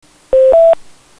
ドミ[MP3]
*2 識別音「プ」または「ドミ」の後に、「プ、プ、プ、プ･･･」と鳴る場合があります。